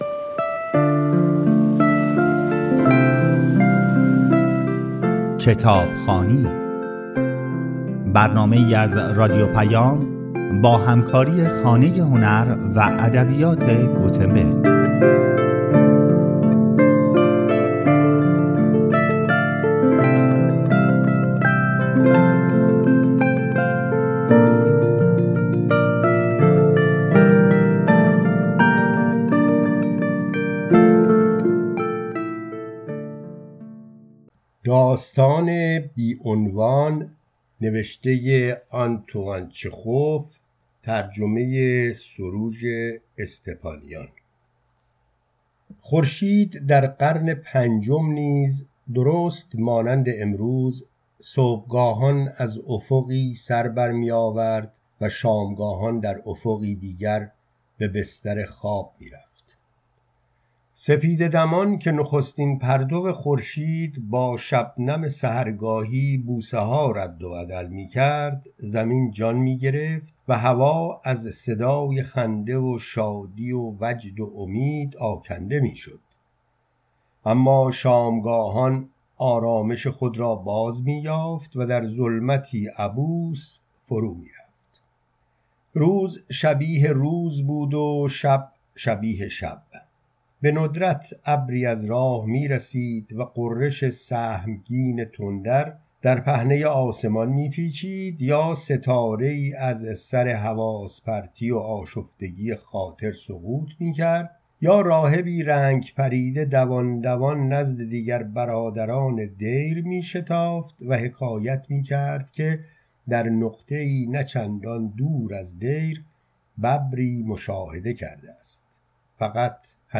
داستان های کوتاه آنتوان چخوف - رادیو پیام